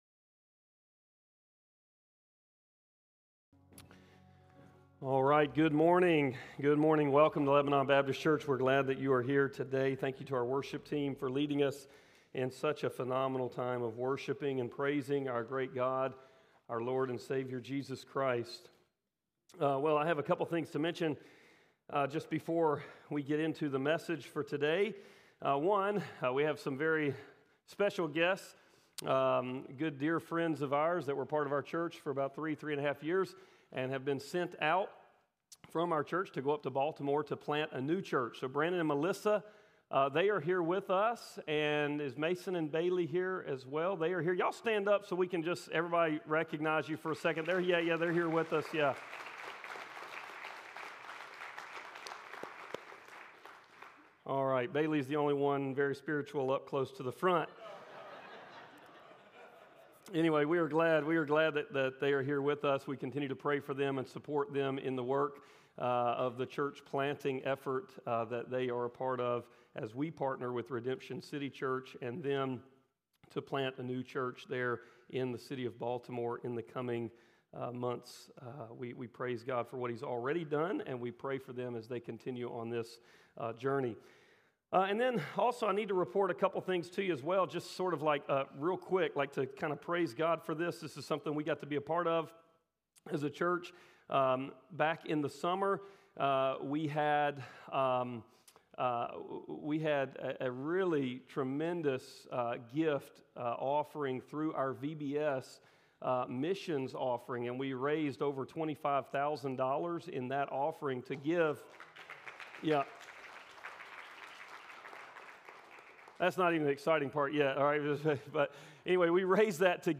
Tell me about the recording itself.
Praying for Gospel Saturation | Lebanon Baptist Church